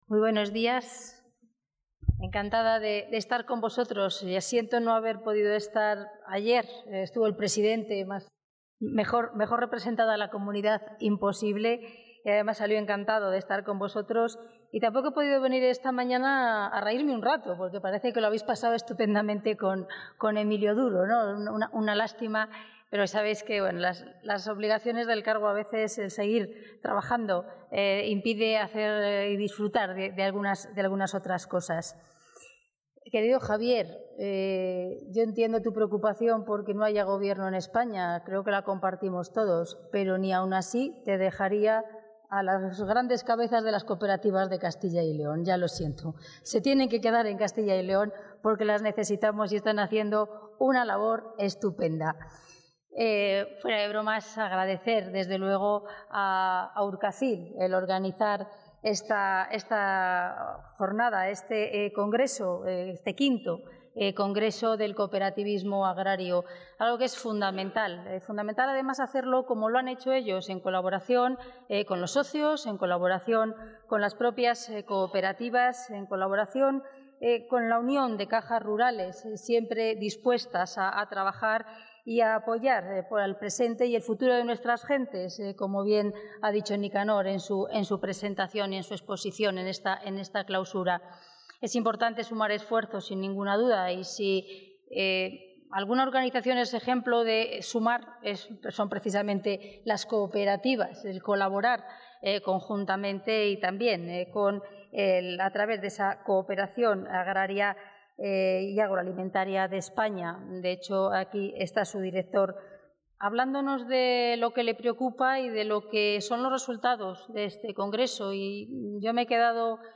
Audio consejera y portavoz.
La consejera de Agricultura y Ganadería y portavoz de la Junta de Castilla y León, Milagros Marcos, ha clausrado hoy en León el V Congreso de Cooperativas Agrarias de Castilla y León.